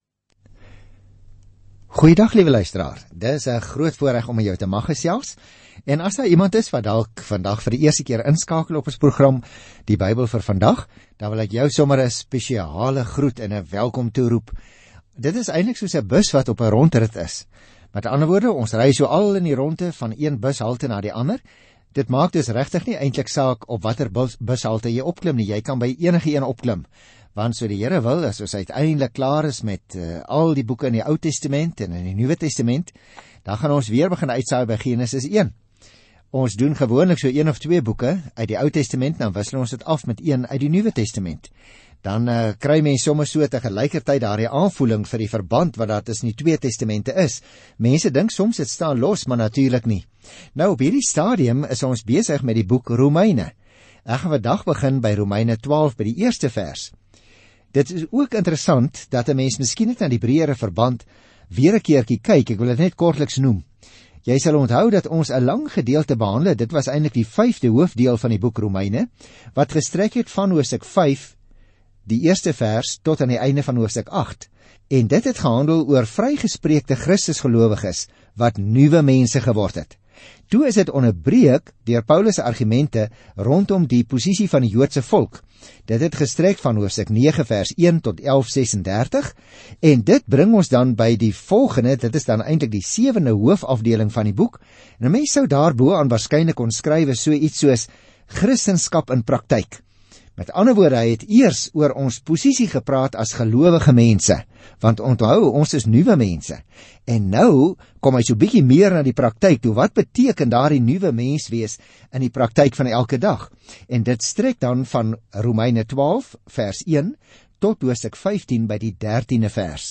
Reis daagliks deur Romeine terwyl jy na die oudiostudie luister en uitgesoekte verse uit God se woord lees.